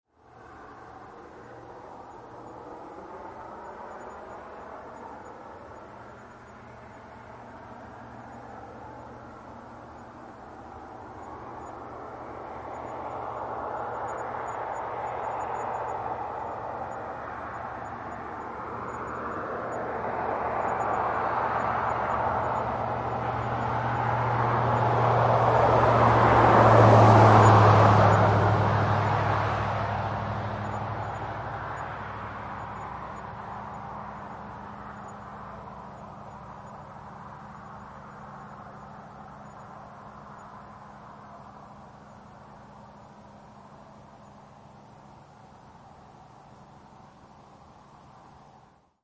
0018 轿车驶过RL
描述：汽车从高速公路从右到左开车。
Tag: 高速公路 现场记录 汽车 driveby